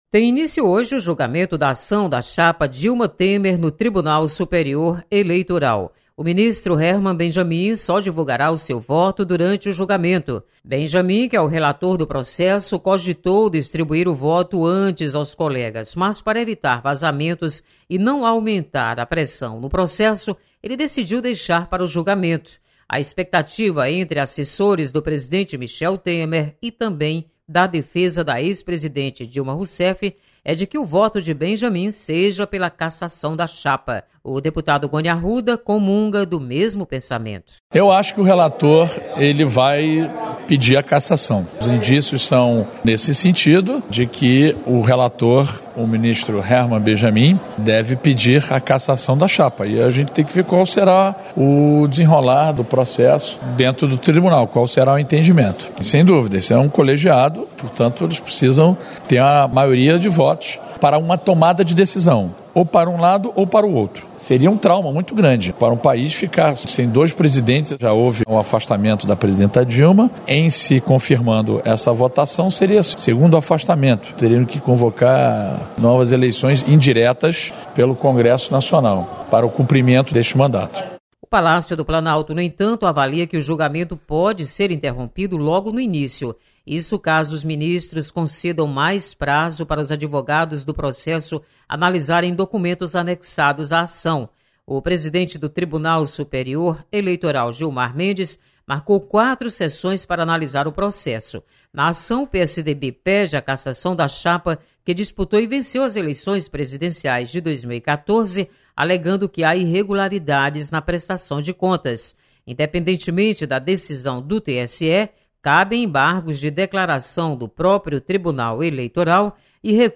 Você está aqui: Início Comunicação Rádio FM Assembleia Notícias TSE